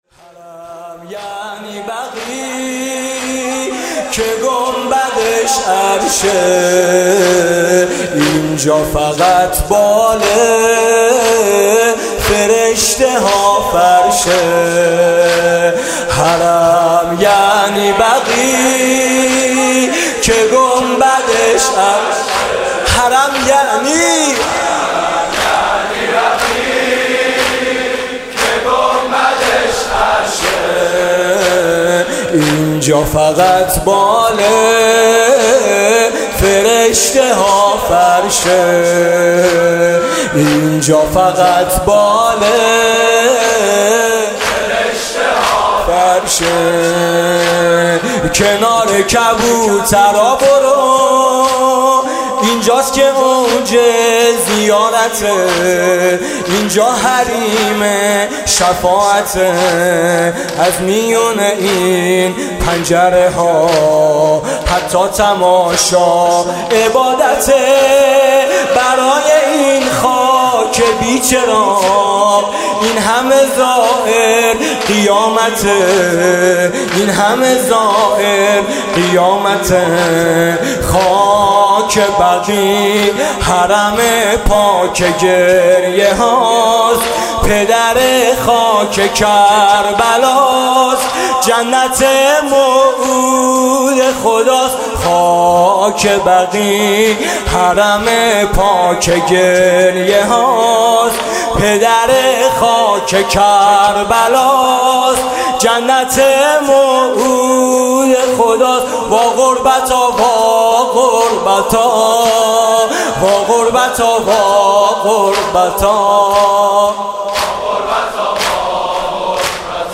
«ویژه مناسبت تخریب بقیع» زمینه: حرم یعنی بقیع، که گنبدش عرشه